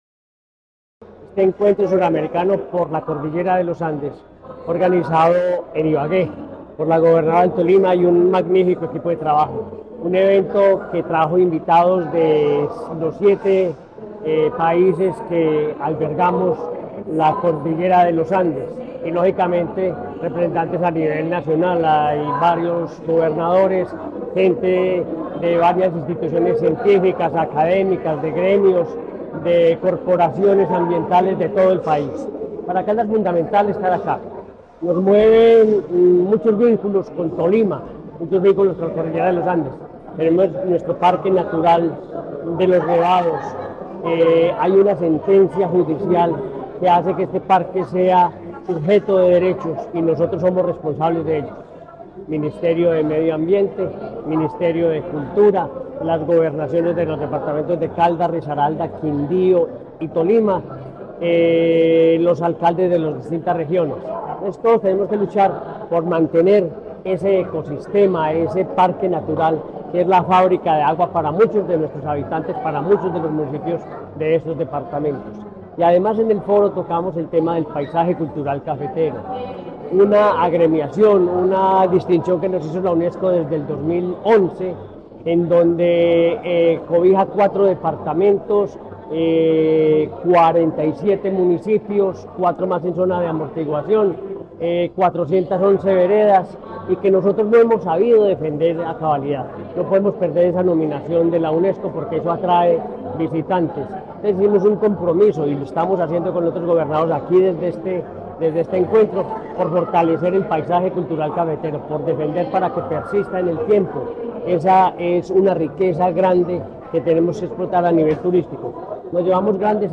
Henry Gutiérrez Ángel, gobernador de Caldas
Gobernador-de-Caldas.mp3